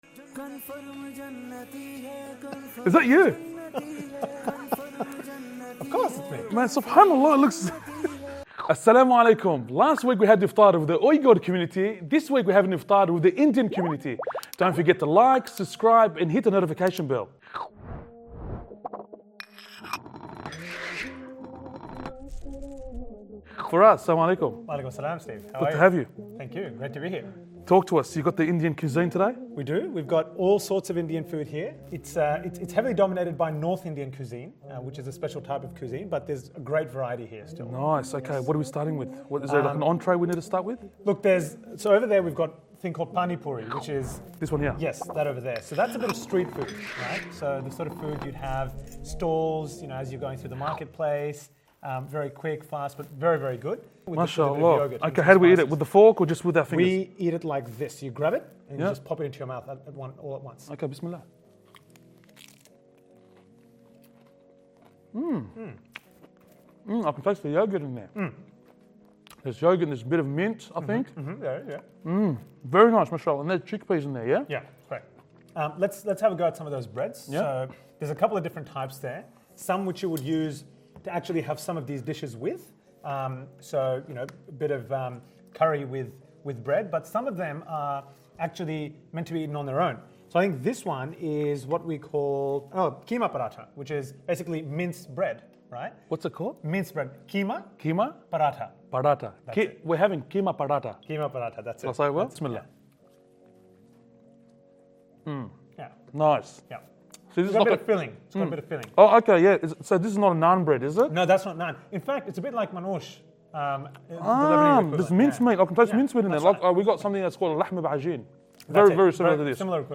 AUSTRALIAN man reacts to INDIAN Ramadan Iftar.mp3